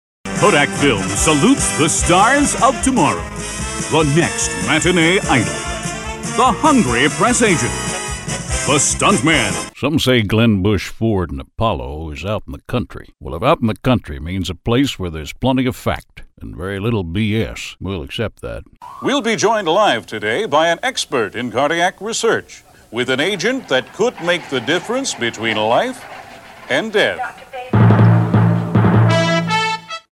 Character Voice Overs / Character Voiceover Actor Demos
Male voice over talent